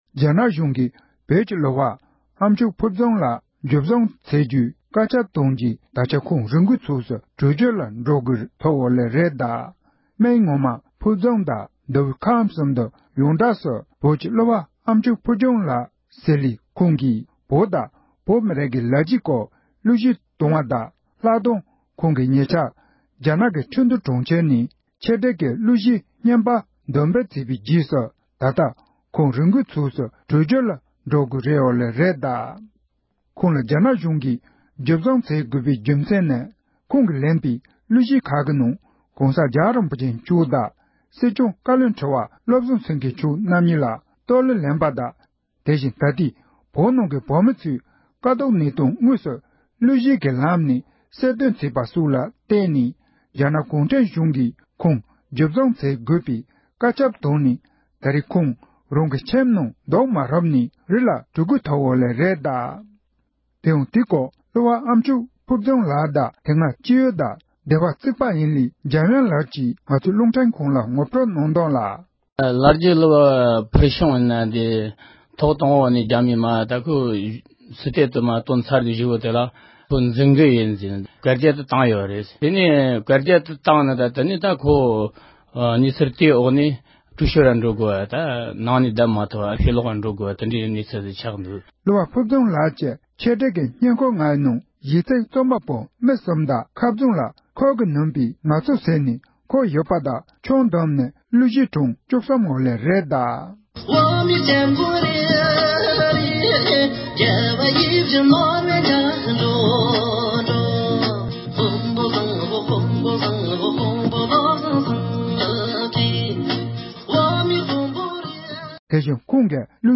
སྒྲ་ལྡན་གསར་འགྱུར། སྒྲ་ཕབ་ལེན།
༄༅༎དེ་རིང་གི་ཁམས་སྐད་ཀྱི་དམིགས་བསལ་གསར་འགྱུར་ནང་རྒྱ་ནག་གཞུང་གིས་བོད་ཀྱི་གླུ་པ་གྲགས་ཅན་ཨམ་མཆོག་ཕུལ་བྱུང་ལགས་འཇུ་བཟུང་བྱ་རྒྱུའི་བཀའ་ཁྱབ་བཏང་རྗེས།